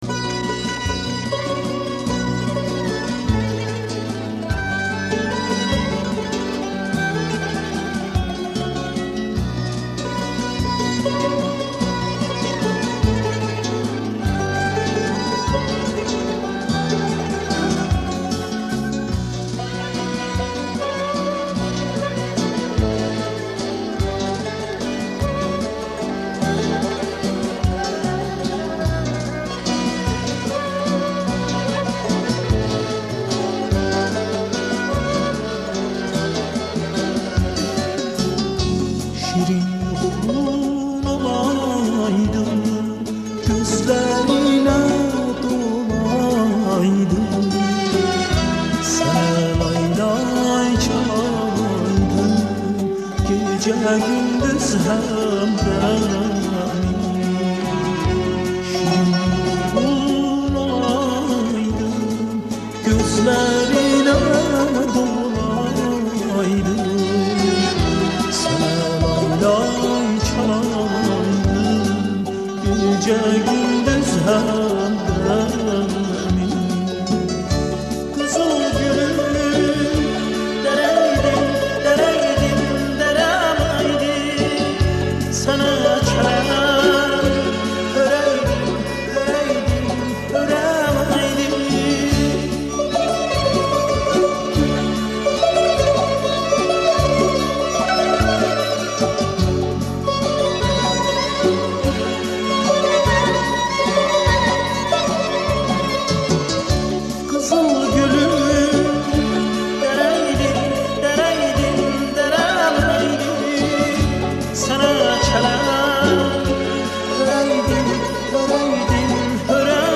دسته بندی : آهنگ ترکی تاریخ : سه‌شنبه 14 دسامبر 2021